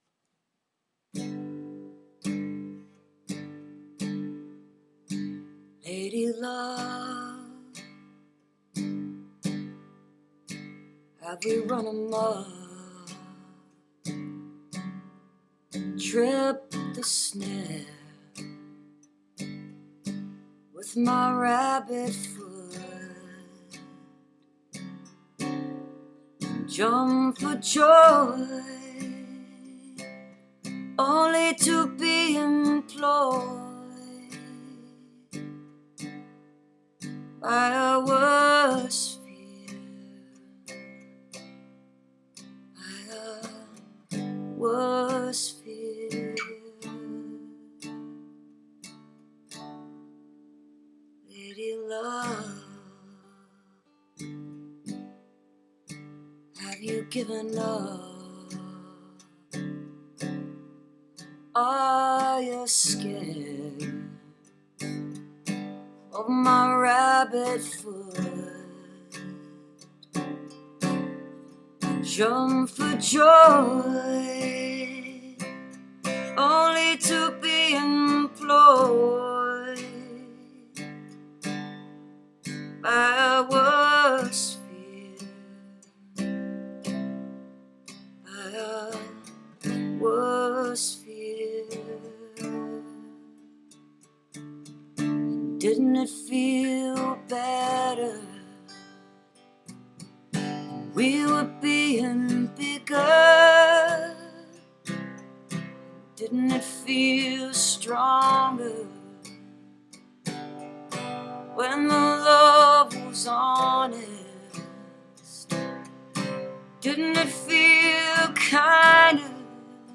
2008. all the performances are acoustic